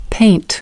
22. Paint  /peɪnt/ : tô màu